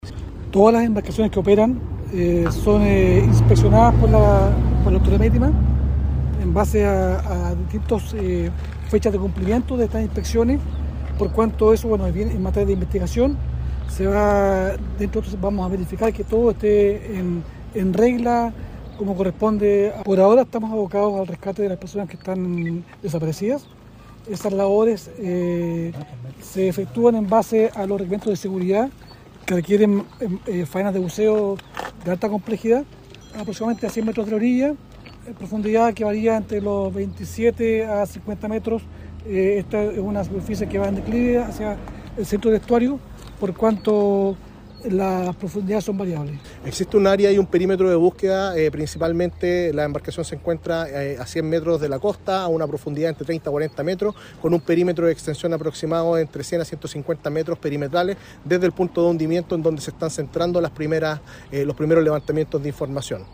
Del mismo modo, respecto de los procedimientos que se llevan a efecto en este momento en la zona se refirió el fiscal marítimo de Puerto Montt, Cristian Astorga.
Por otra parte el director de Senapred Víctor Riquelme destacó las labores de las instituciones en conjunto en esta compleja tarea de dar con el paradero de los desaparecidos.
FISCAL-MARITIMO-DIRECTOR-SENAPRED.mp3